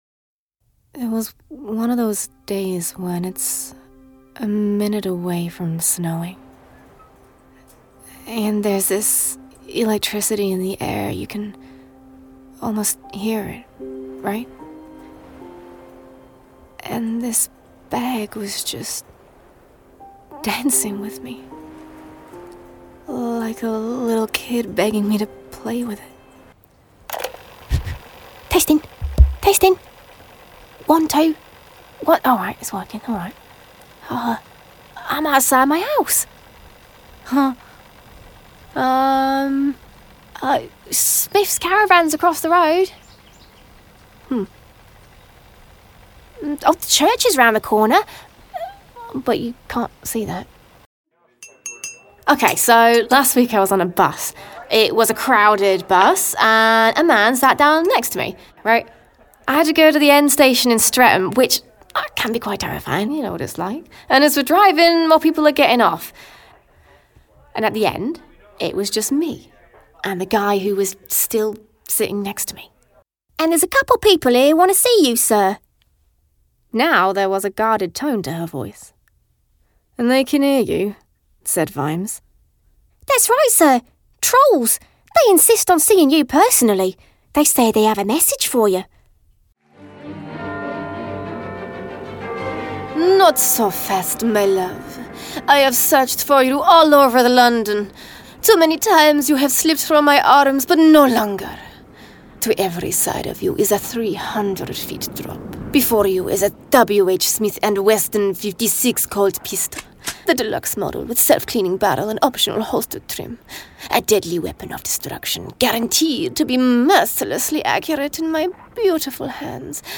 Radio Drama Showreel
Female
Neutral British
Bright
Friendly
Upbeat
Warm